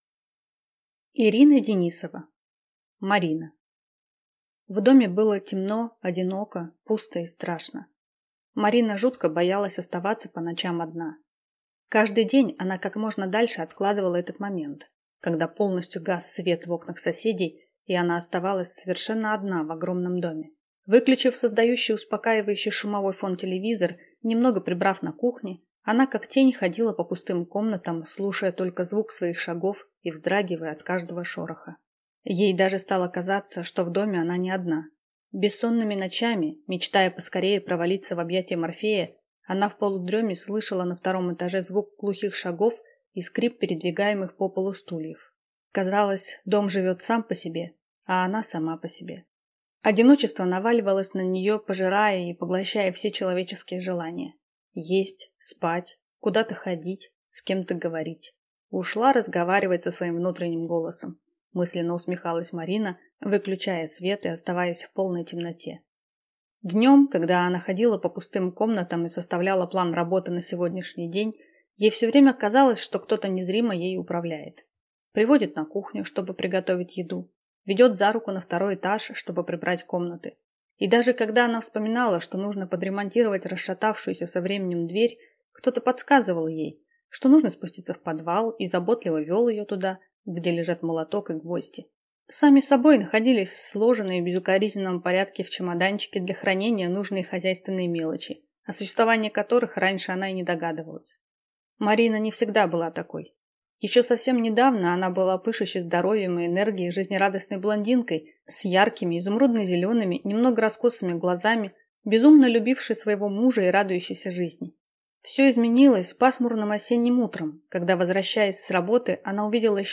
Аудиокнига Марина | Библиотека аудиокниг